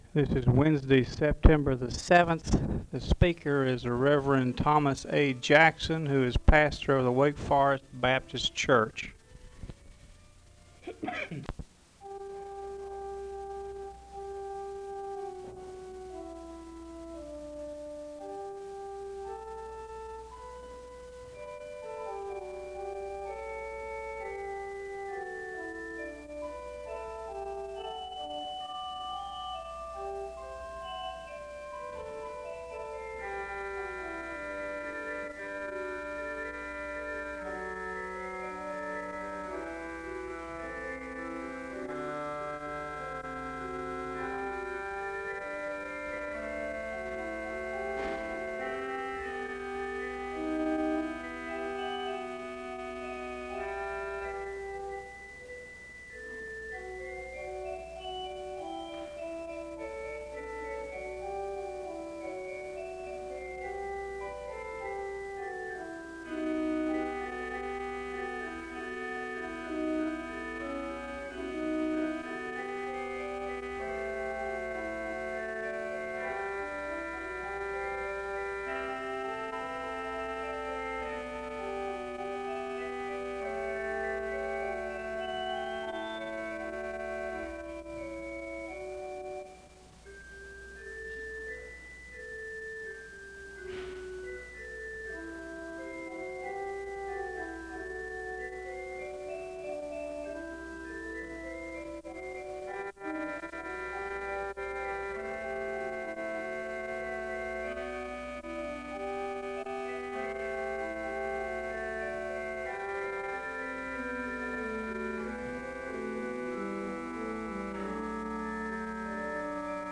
Two hymns are played (0:11-5:15). Scripture reading is followed by a hymn (cut) (5:16-5:54). A request for prayer is announced, and a word of prayer is given (5:55-9:22).
The choir sings an anthem (11:19-13:21).
SEBTS Chapel and Special Event Recordings SEBTS Chapel and Special Event Recordings